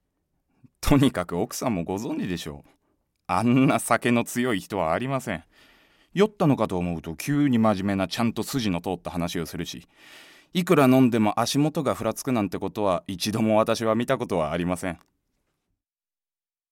セリフ@
ボイスサンプル